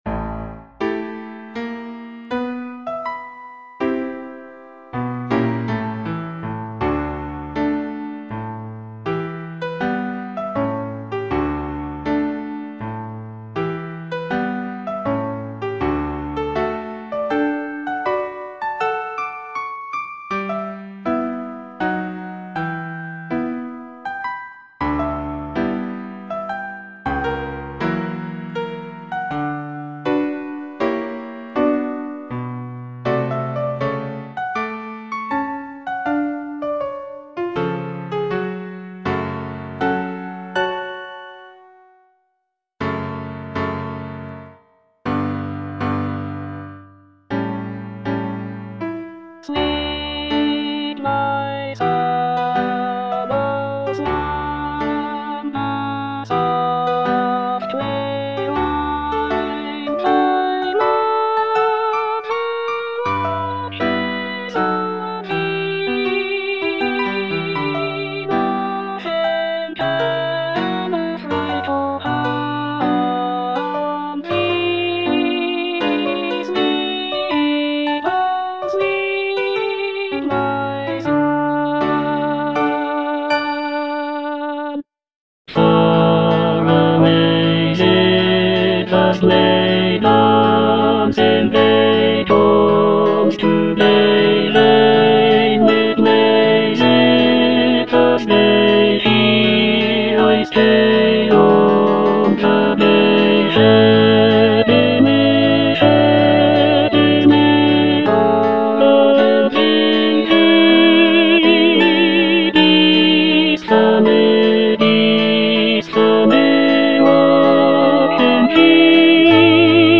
E. ELGAR - FROM THE BAVARIAN HIGHLANDS Lullaby (tenor I) (Emphasised voice and other voices) Ads stop: auto-stop